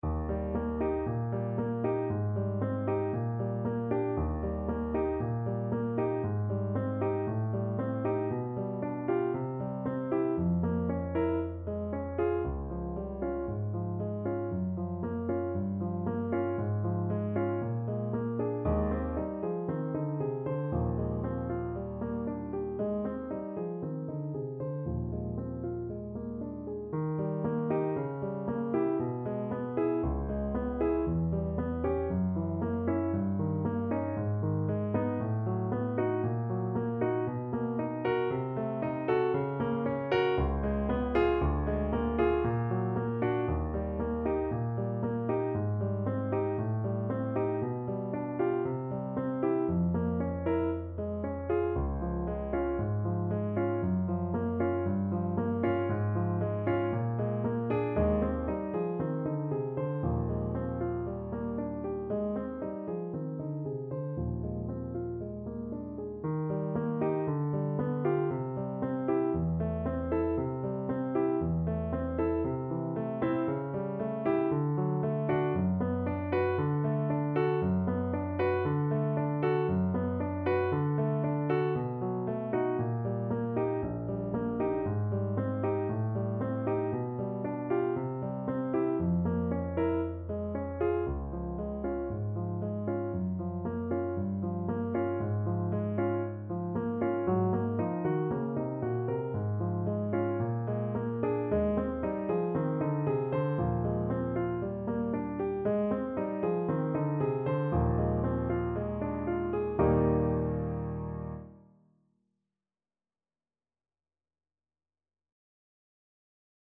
Allegro moderato =116 (View more music marked Allegro)
Classical (View more Classical Trombone Music)